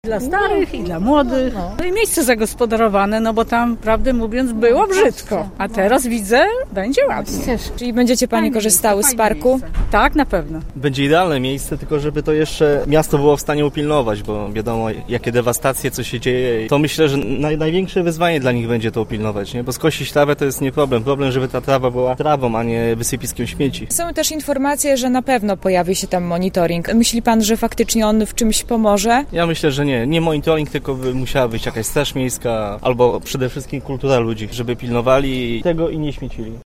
Mieszkańcy miasta mówią, że lokalizacja parku była dobrą decyzją, a sam pomysł na jego wybudowanie oceniają pozytywnie: